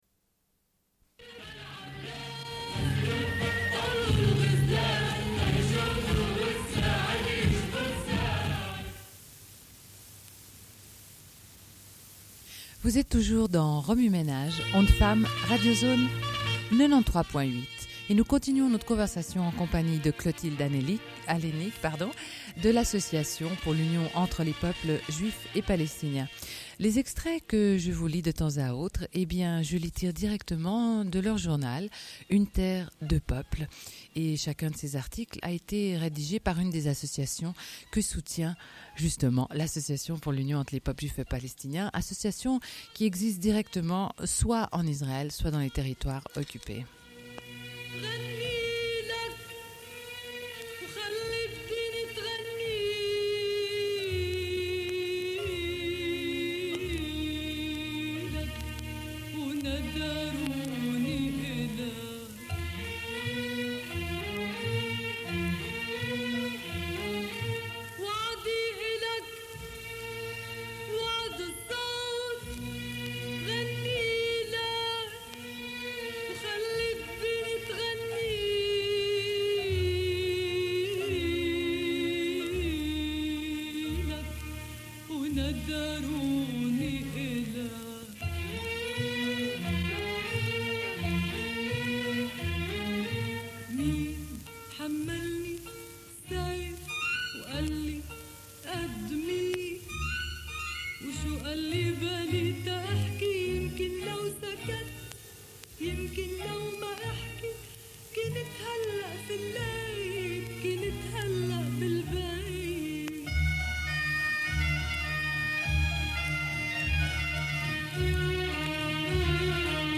Une cassette audio, face A00:31:32